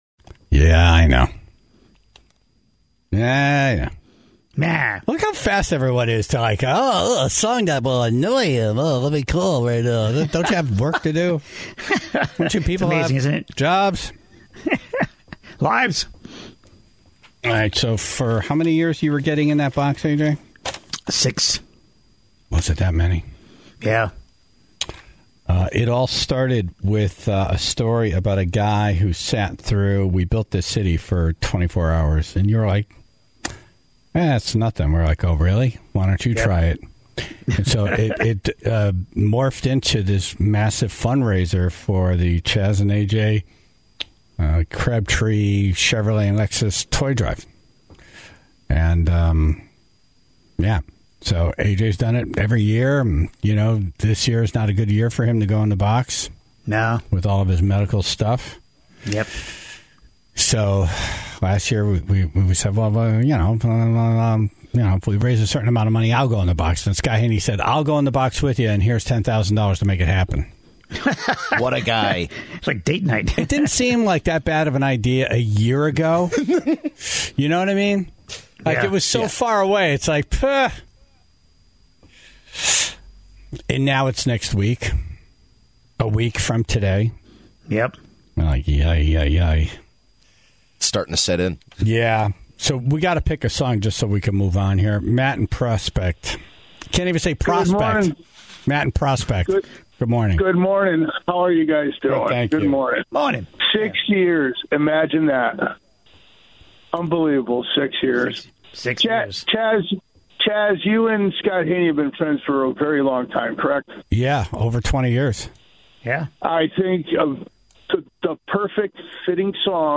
One of the last pieces to put in place, is the song that will play the entire time on an endless loop. The Tribe called in the songs they think will provide the most entertainment for everyone,...